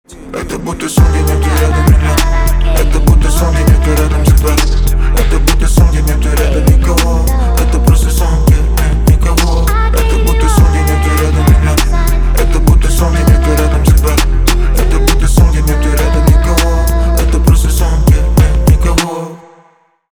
русский рэп , битовые , басы
грустные
печальные